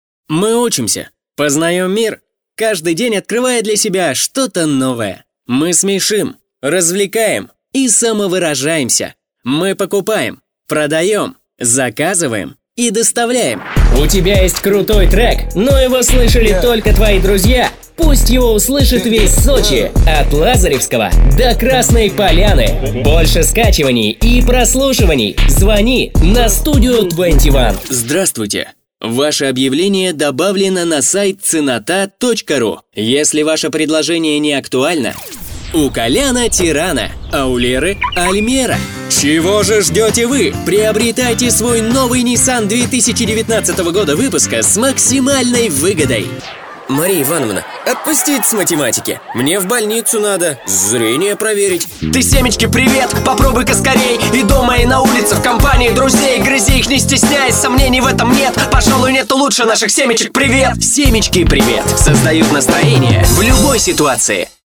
Яркий и свежий голос для ваших проектов :) Отлично звучу в молодежных динамичных роликах, развлекательном контенте для YouTube, автоответчиках и различных игровых сценариях.
Тракт: Подготовленное помещение, микрофоны: Recording Tools MC 900, Предусилитель DBX 376; Аудиоинтерфейс: Audient iD14
Демо-запись №1 Скачать